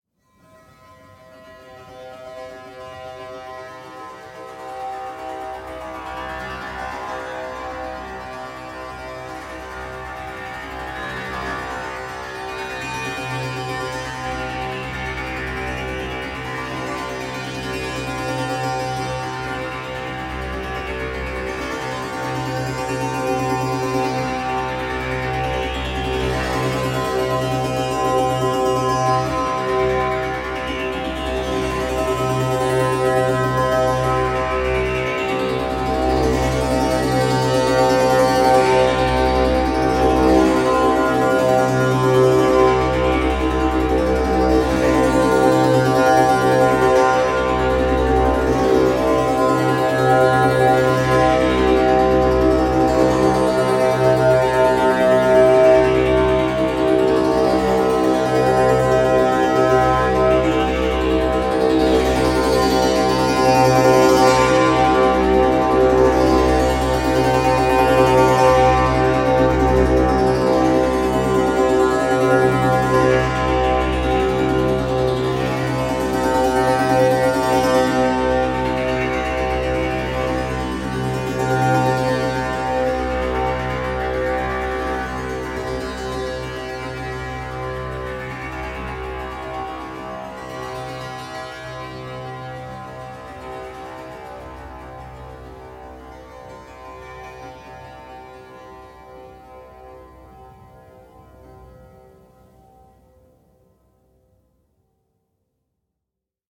Monochord Therapy sequence octave C - Cm - 107.mp3
Original creative-commons licensed sounds for DJ's and music producers, recorded with high quality studio microphones.
monochord_therapy_sequence_octave_c_-_cm_-_107_1kw.ogg